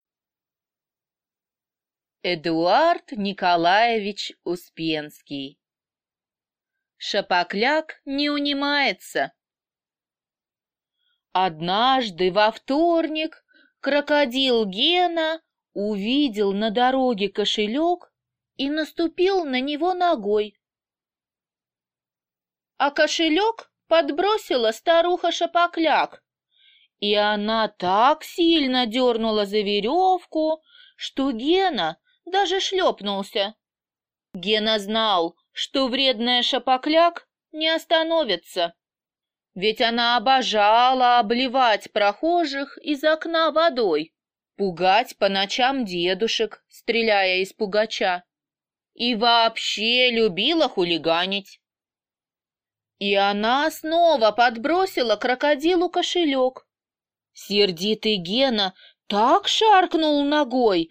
Аудиокнига Шапокляк не унимается | Библиотека аудиокниг